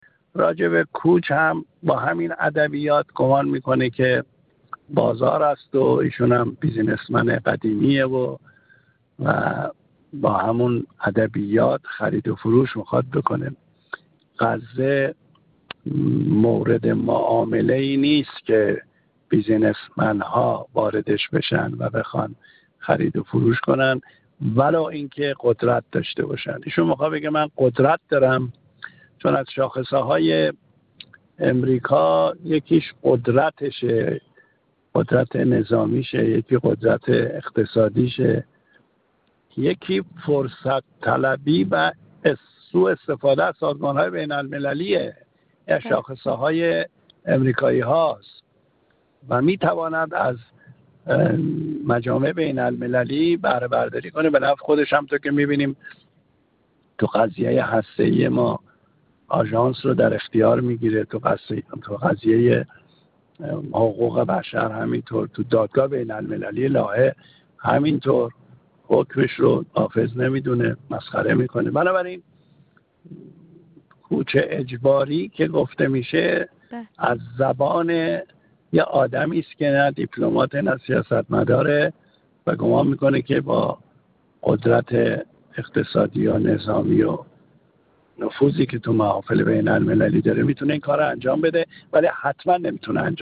محمدرضا باقری، معاون اسبق وزیر امور خارجه و سفیر پیشین کشورمان در کویت
گفت‌وگو